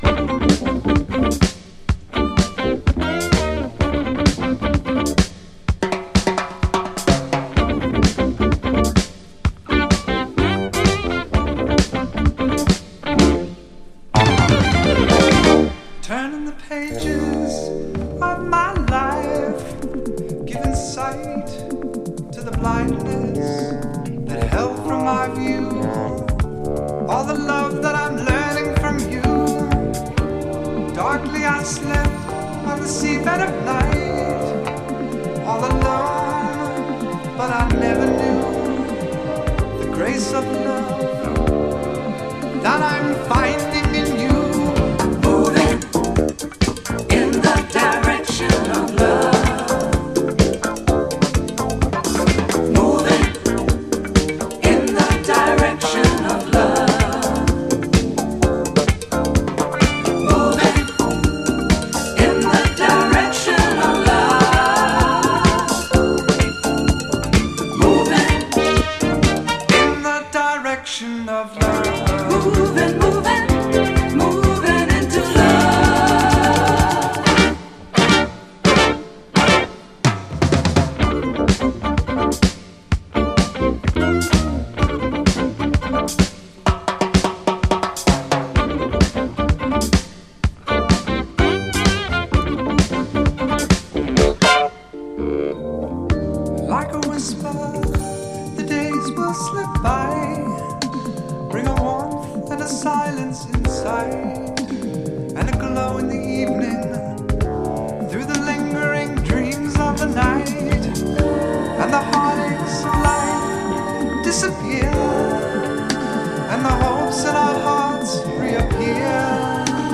DISCO
UKのプロデューサーによるオブスキュアUKディスコ！
メロウな展開も挟みつつドラマティックに盛り上がっていきます。